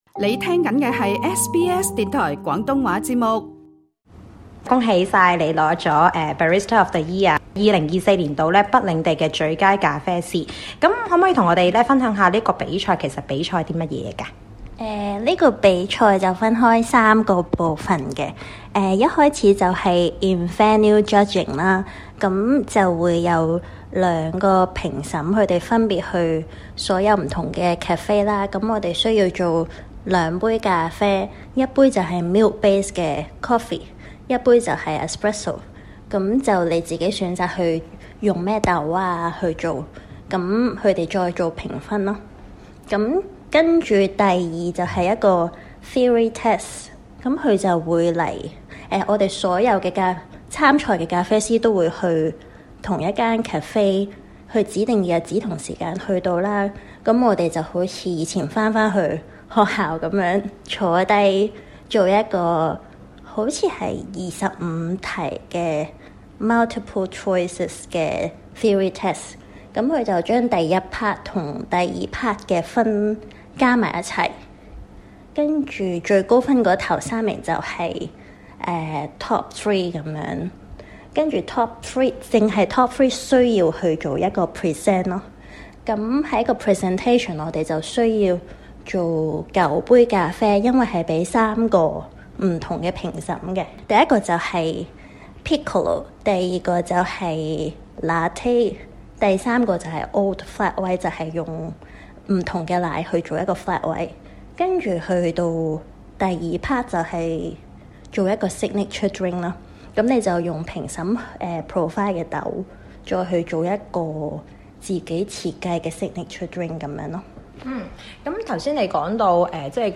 整個比賽過程需要「過三關」，包括理論、實戰...... 當然還要自製咖啡特飲(Signature Coffee)。詳情可以收聽足本訪問。